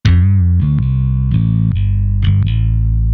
Beim Trillian entsteht dann allerdings ein leichtes Knacksen und zwar an der Stelle, wo sich Noten überlappen.
Es fällt besonders bei tiefen Noten auf und vor allem dann, wenn die überlappende (also zweite) Note eine niedrige Veloctiy hat.
Habe mal ein Beispiel angehangen, bei dem drei mal dieses Knacksen zu hören ist.